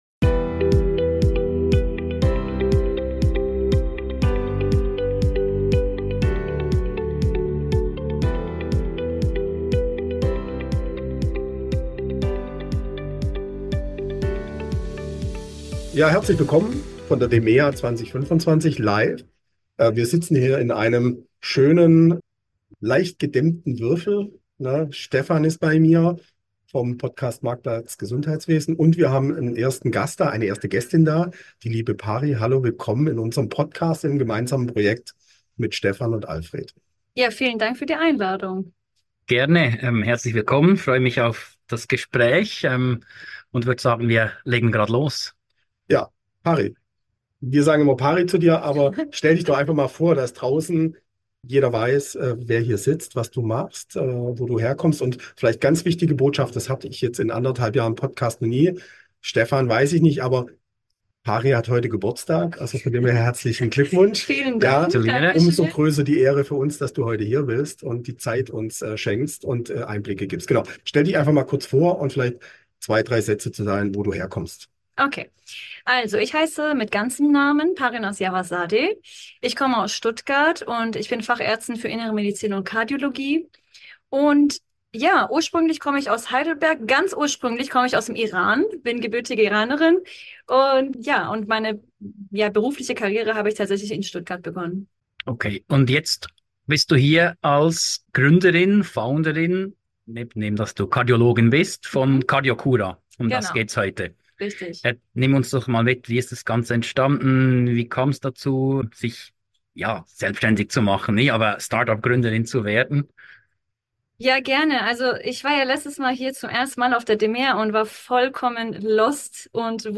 Zwei inspirierende Interviews von der DMEA 2025